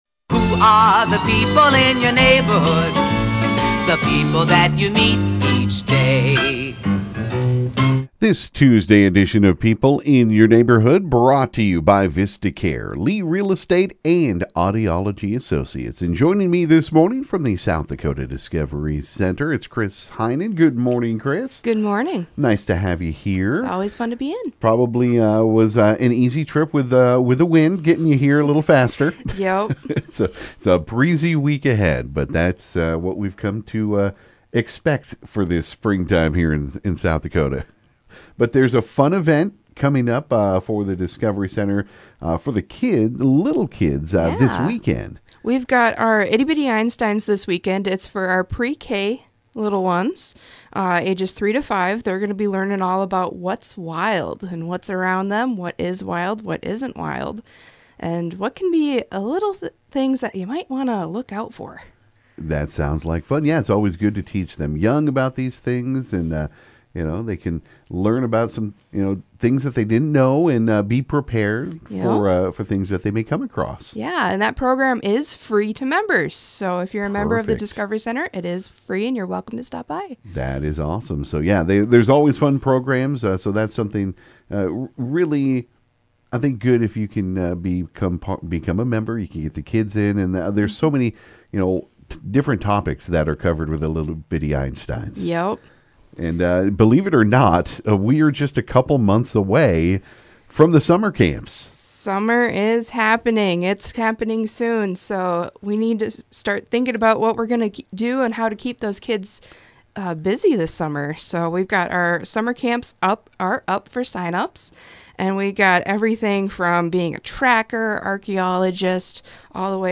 stopped into the KGFX studio